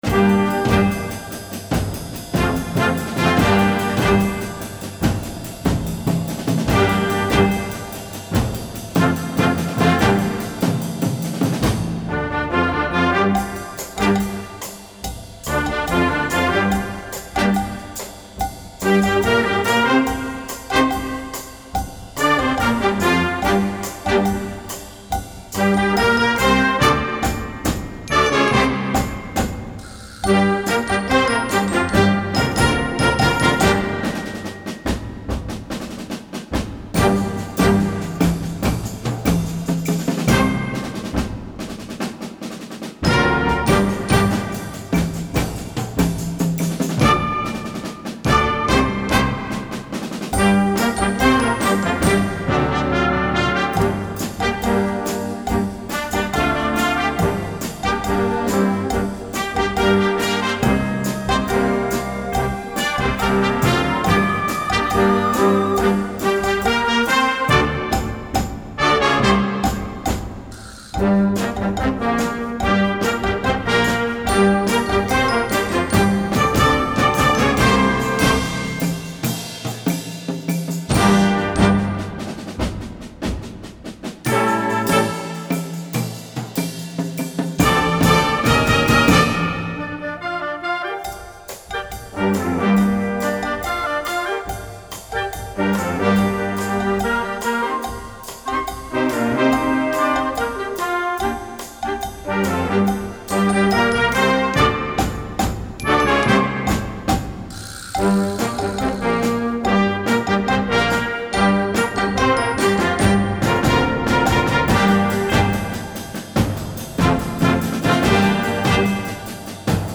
Voicing: Percussion Section w/ Band